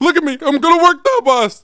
welder-getshot3.mp3